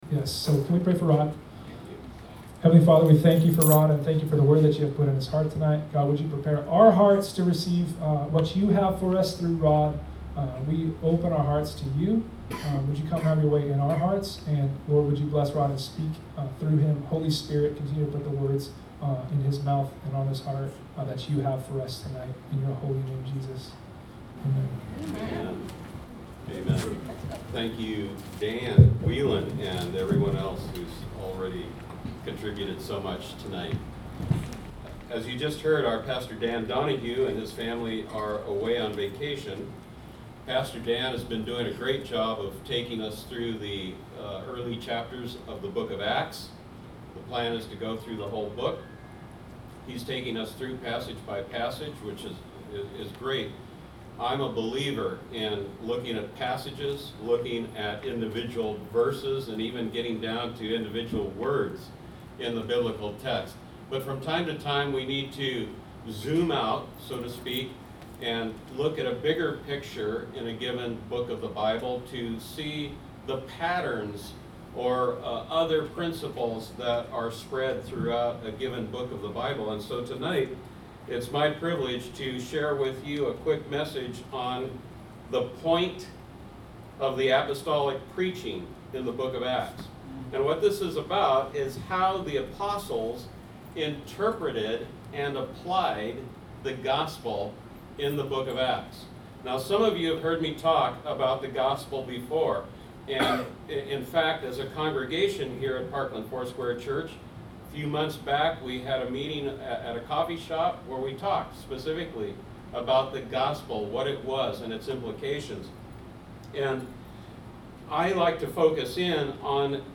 The Point Of The Apostolic Preaching In The Book Of Acts A message about how the apostles interpreted and applied the gospel.